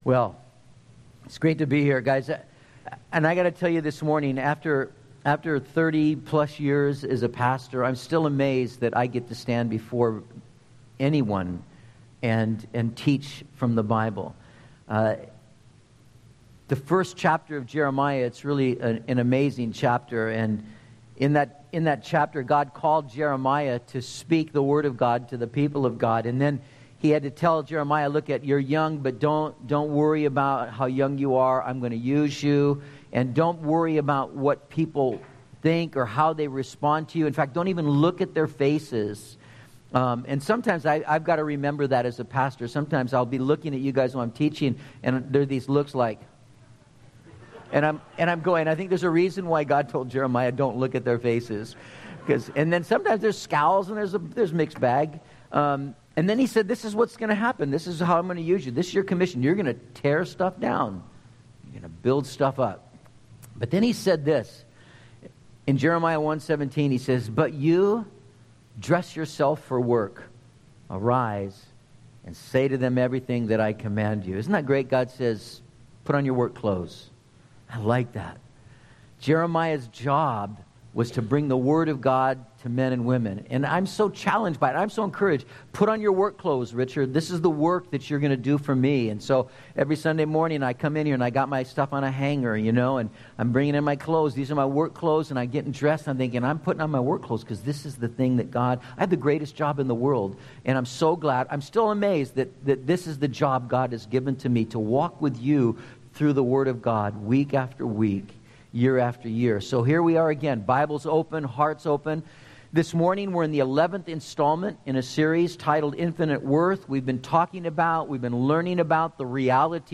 Content from Metro Calvary Sermons